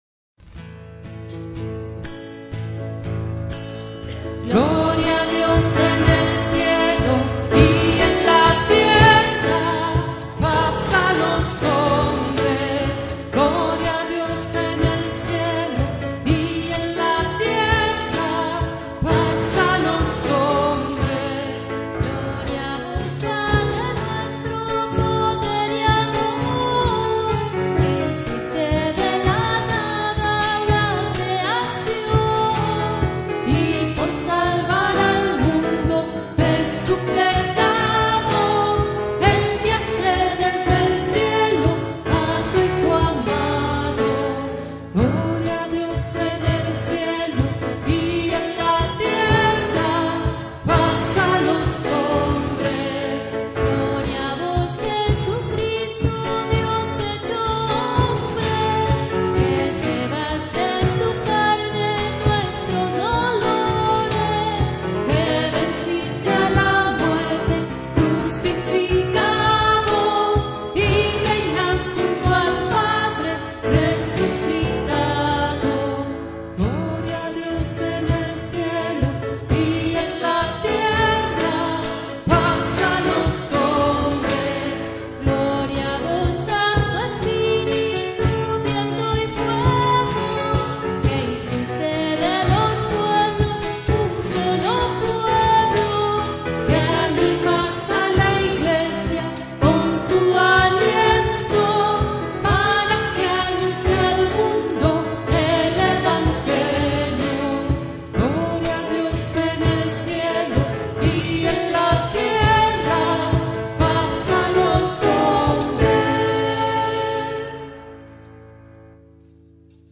voz y guitarra
piano